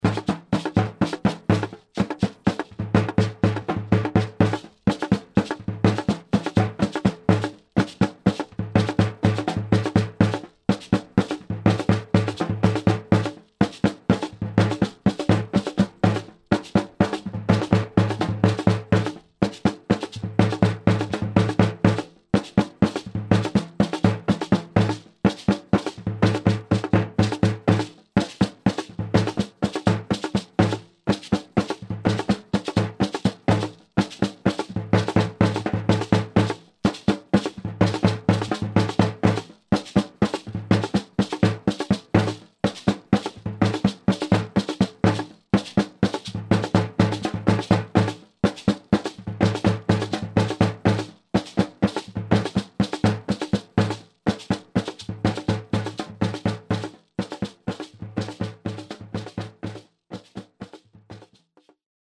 The ginguang bik is a double-skin cylindrical drum that belongs to the family of the gingaung.
This drum is struck with a drumstick in one hand and the other hand is used to gently damp the membrane.
The ginguang bik produces a clear sound and is well suited to playing the middle register.